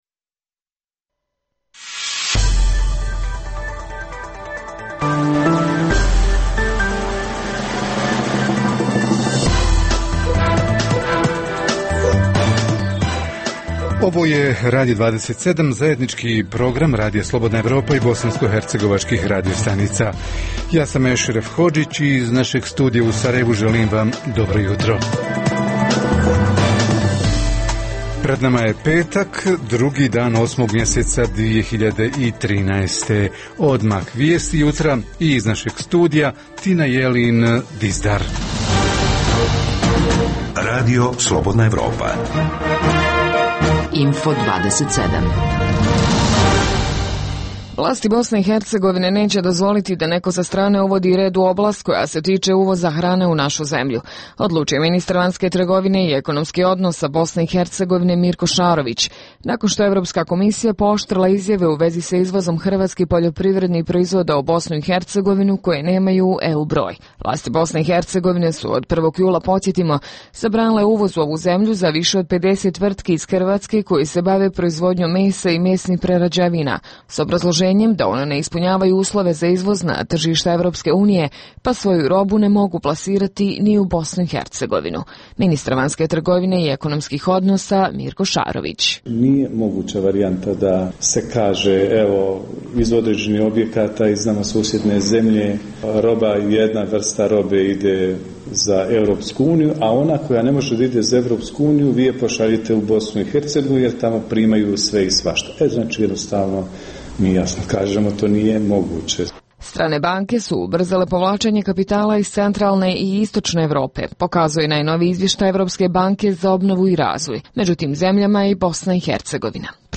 - Susret uživo sa Sarajevom: od jučer voda je skuplja za 20-ak procenata, ali ne za one koji budu trošili manje od 5 kubika - od 1. septembra i centralno grijanje biće skuplje, također za 20 odsto – hoće li socijalno najugroženiji dobiti subvenciju? Na ova i druga pitanja jutros odgovara kantonalni ministar prostornog uređenja i okološa Zlatko Petrović.